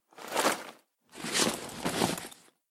liz_backpack_equip.ogg